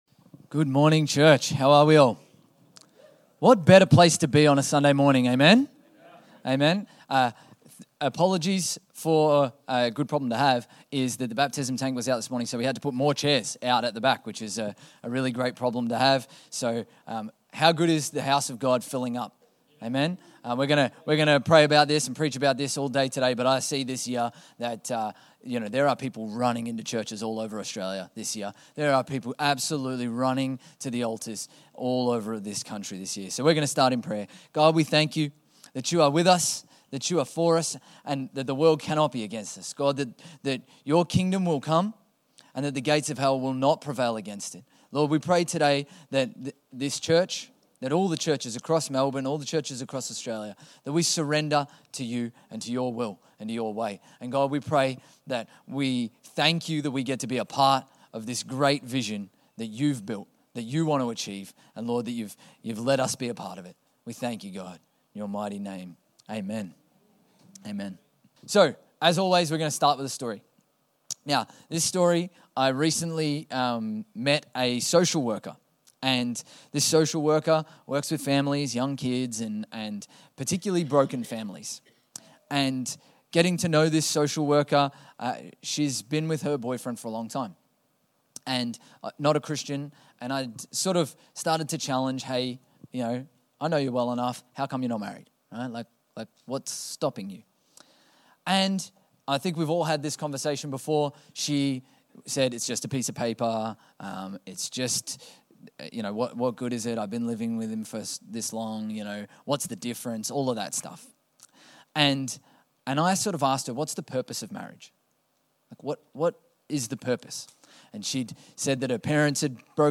Infinity Church Podcast - English Service | Infinity Church
Current Sermon